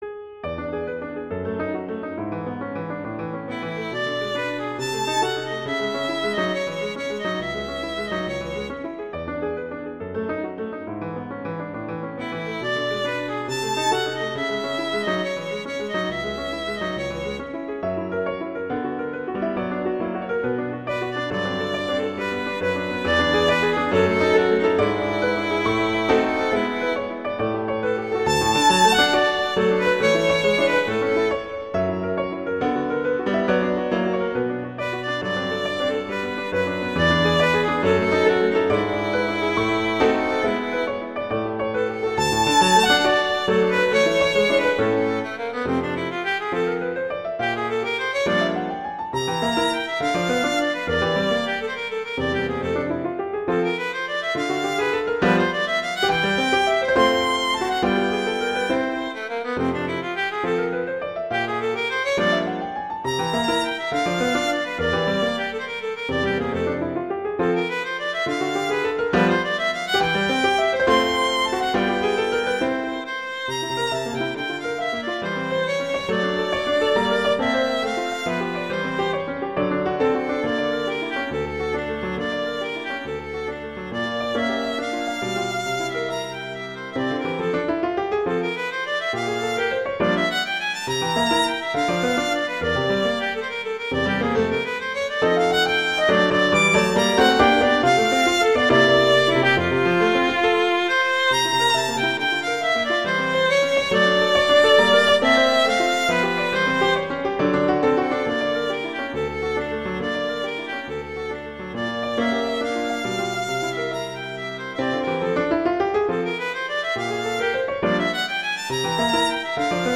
classical
A minor, A major